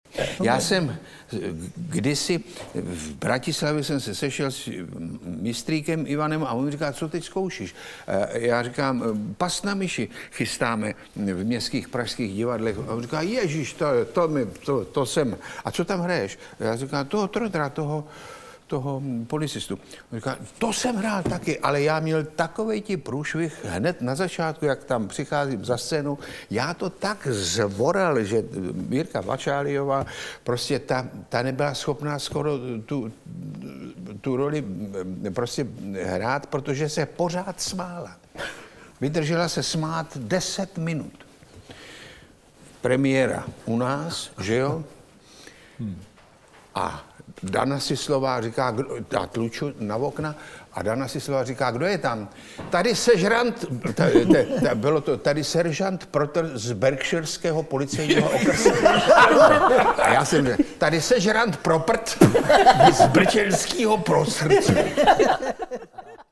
5 trumfů v hrsti - Hvězdy vyprávějí 2 audiokniha
Na sklonku roku 2017 se sešla ve Werichově vile vybraná společnost.
Ukázka z knihy
• InterpretPetr Štěpánek, Petr Kostka, Václav Postránecký, František Němec, Václav Knop